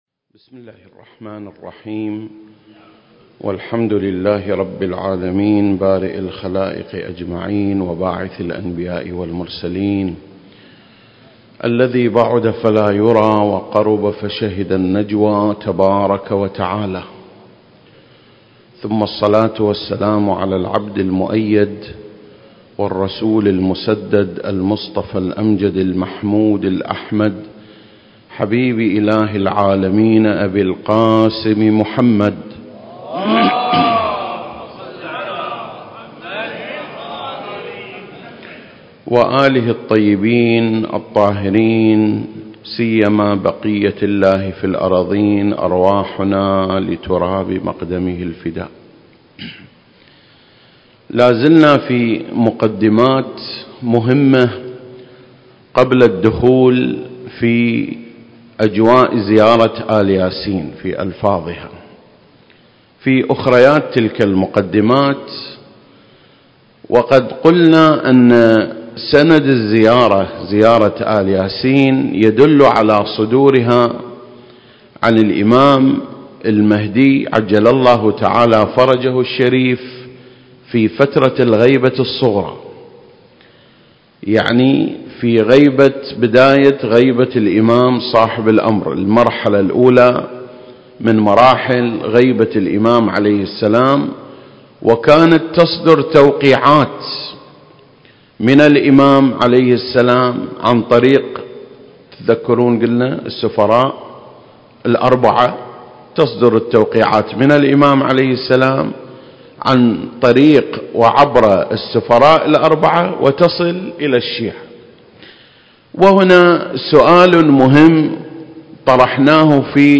سلسلة: شرح زيارة آل ياسين (9) - أدلة صدور التوقيعات من الإمام المهدي (عجّل الله فرجه) المكان: مسجد مقامس - الكويت التاريخ: 2021